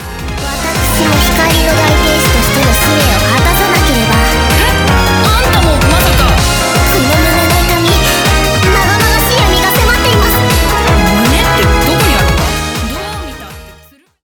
幼女電波ソング